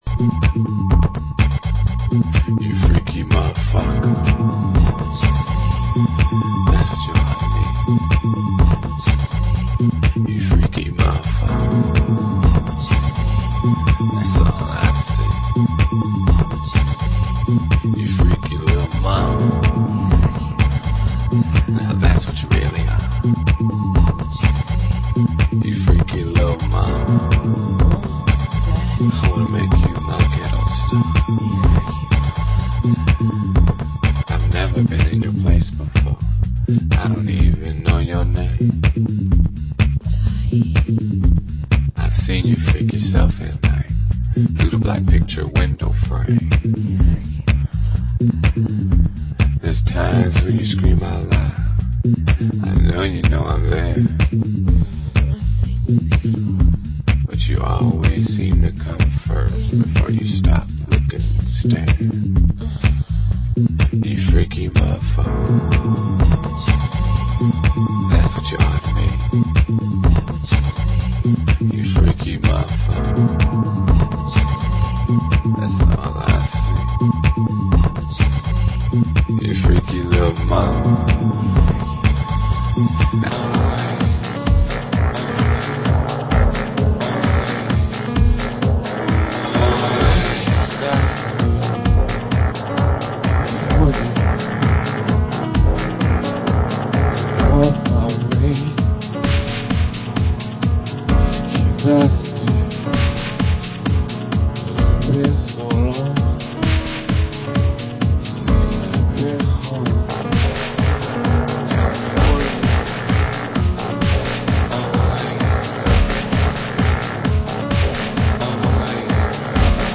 Genre: Detroit Techno